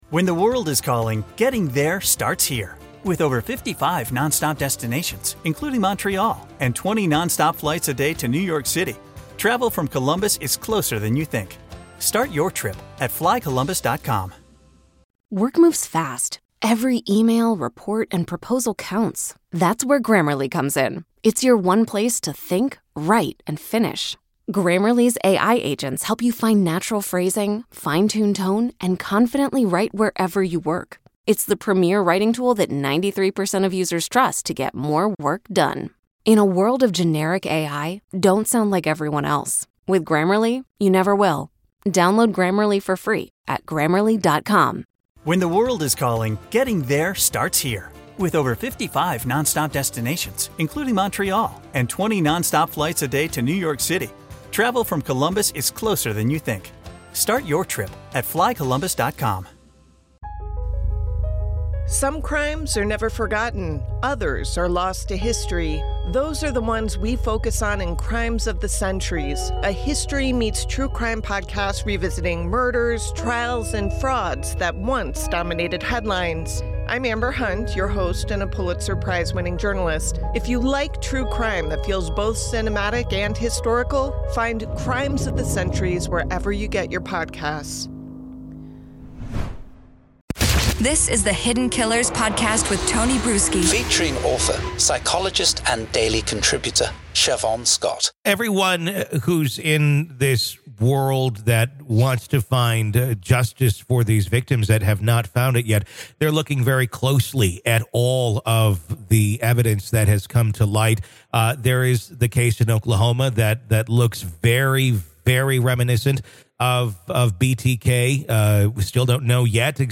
In a revealing conversation on the "Hidden Killers" podcast